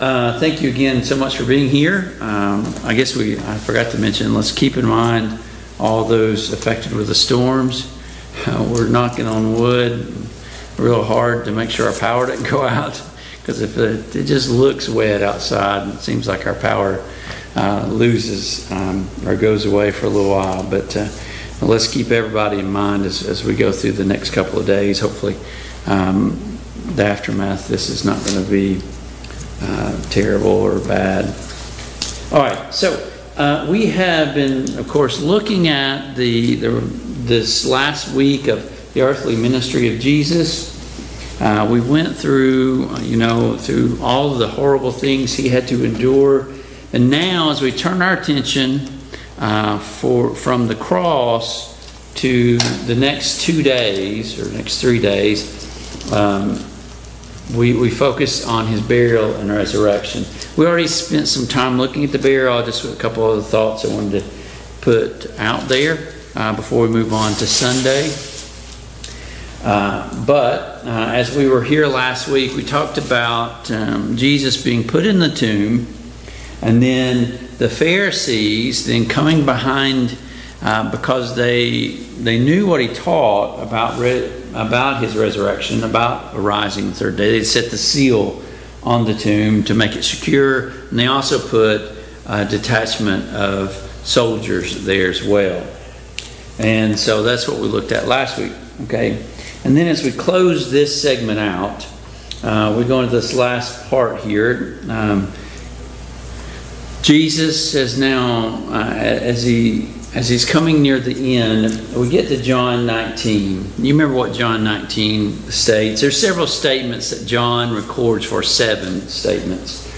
Biblical Timeline of Jesus' Resurrection Service Type: Mid-Week Bible Study Download Files Notes Topics: Jesus' Resurrection